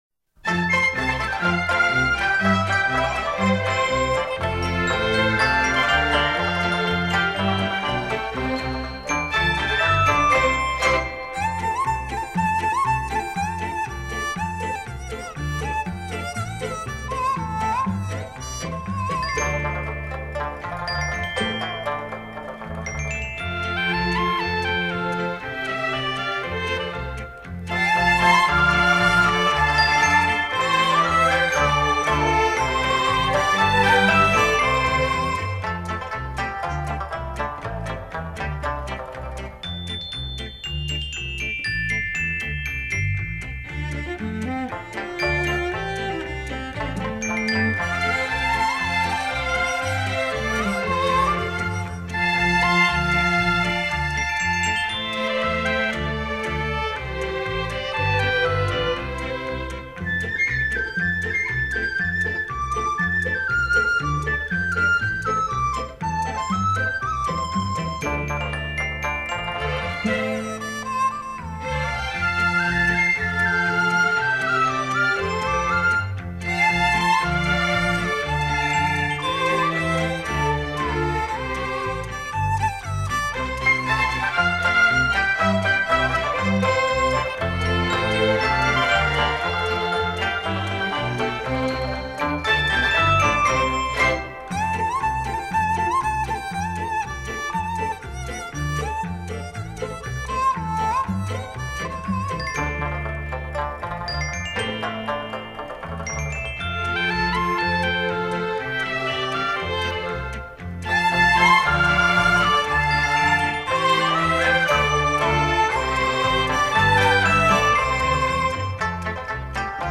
录制方式: CD转320K=MP3      .